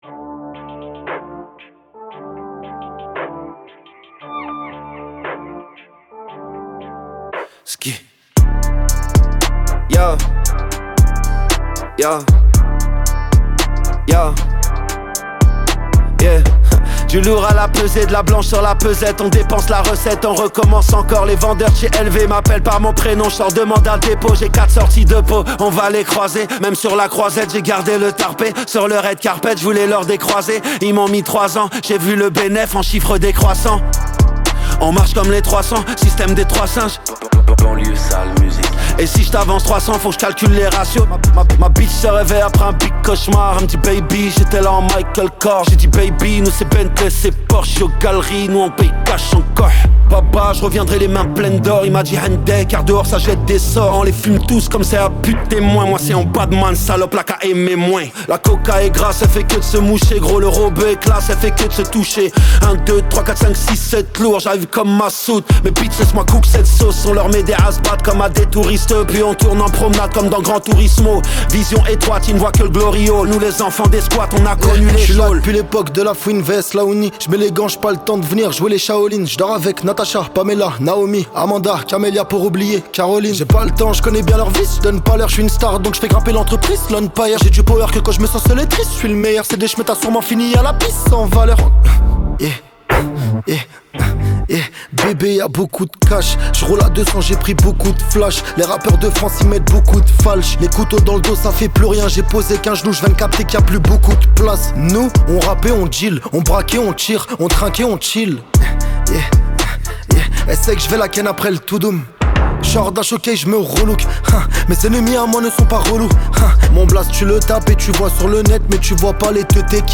23/100 Genres : french rap, french r&b Écouter sur Spotify